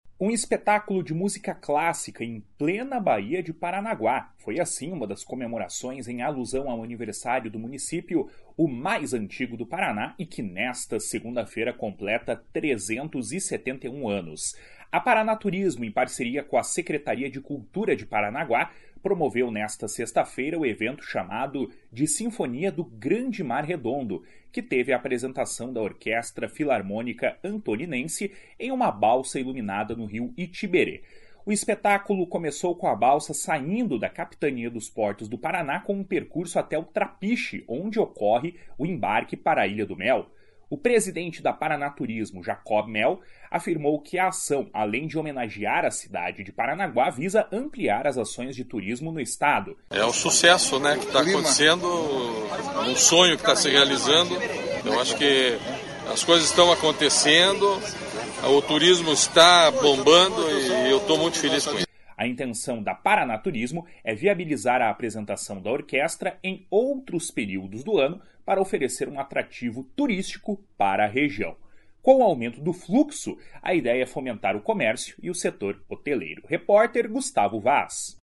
O presidente da Paraná Turismo, Jacob Mehl, afirmou que a ação, além de homenagear a cidade de Paranaguá, visa ampliar as ações de turismo no Estado. // SONORA JACOB MEHL // A intenção da Paraná Turismo é viabilizar a apresentação da orquestra em outros períodos do ano para oferecer um atrativo turístico para a região.